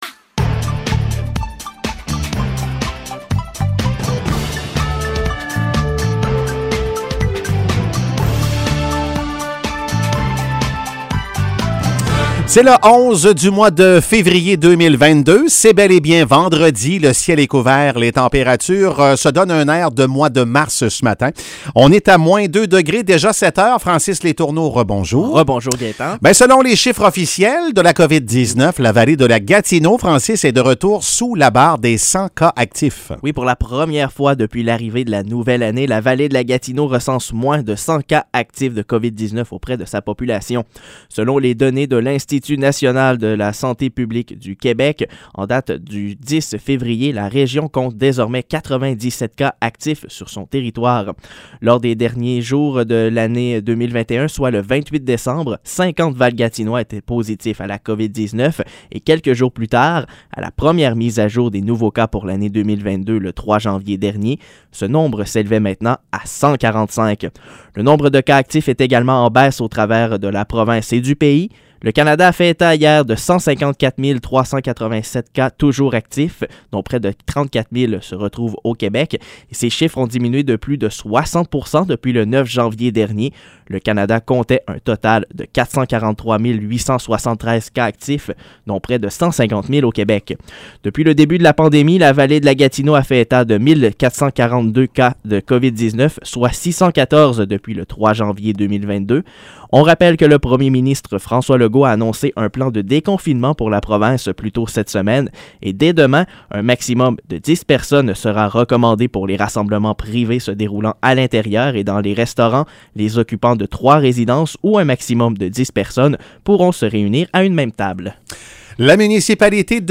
Nouvelles locales - 11 février 2022 - 7 h